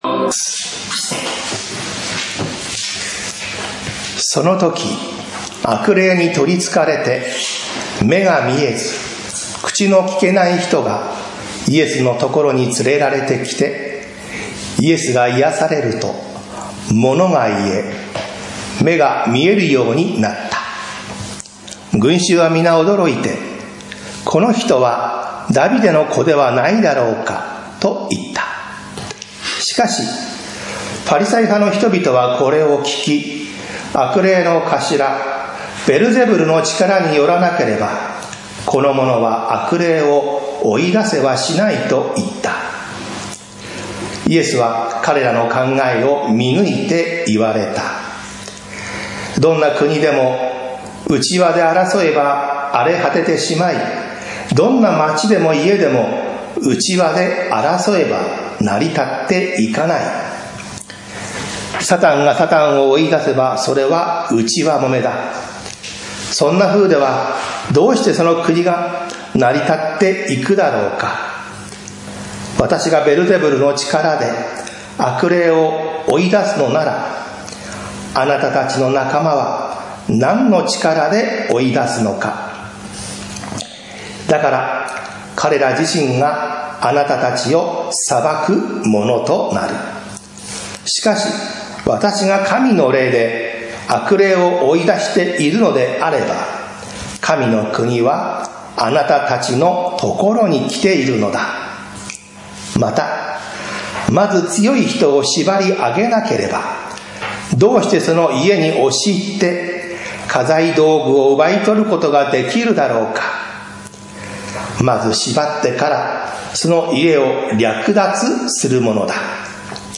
聖霊に言い逆らう者の末路 宇都宮教会 礼拝説教